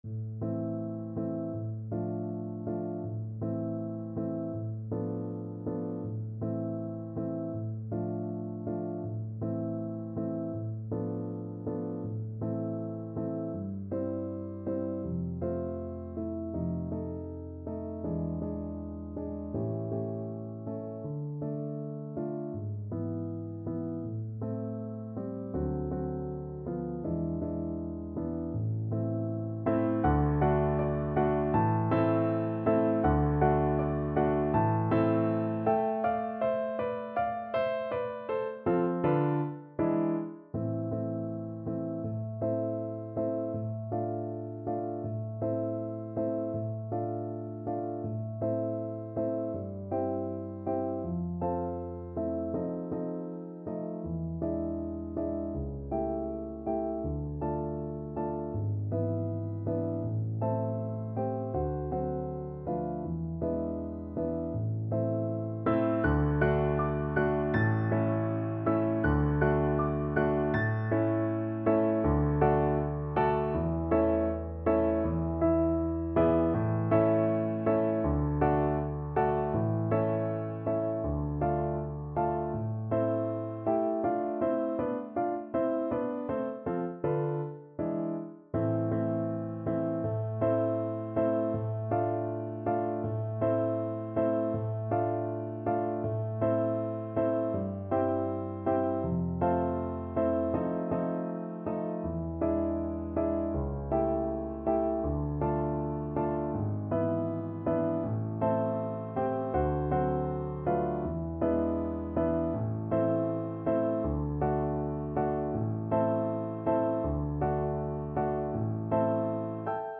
Play (or use space bar on your keyboard) Pause Music Playalong - Piano Accompaniment Playalong Band Accompaniment not yet available transpose reset tempo print settings full screen
~ = 100 Andante
A minor (Sounding Pitch) (View more A minor Music for Flute )
2/4 (View more 2/4 Music)
Classical (View more Classical Flute Music)